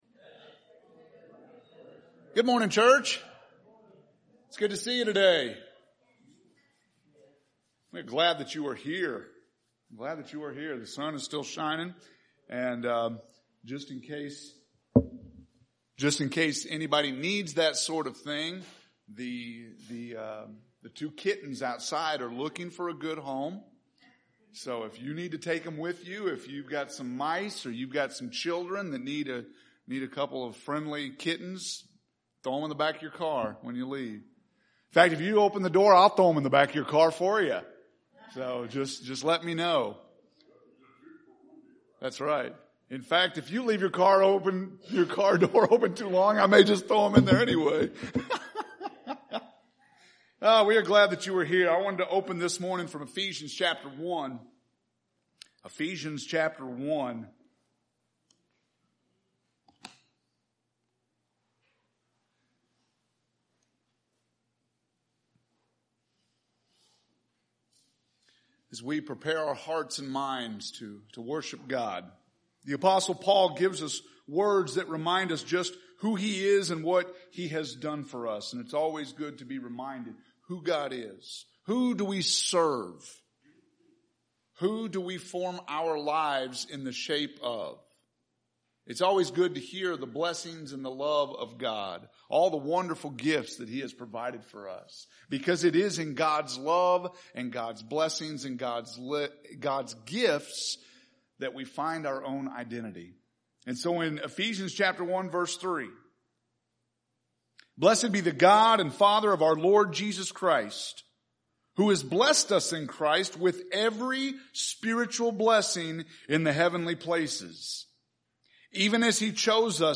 August 23rd – Sermons